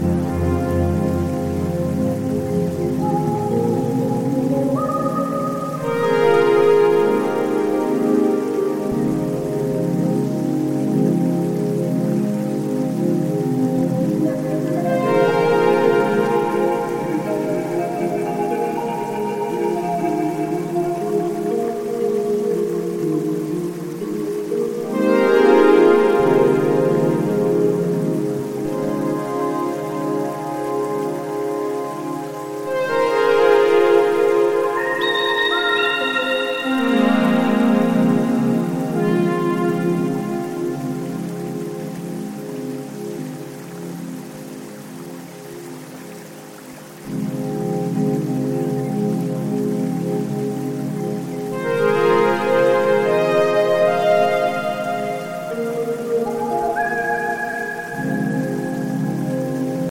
壮大なサウンドスケープは、忙しない日常の心の支えとなるメディテーションに没入できます。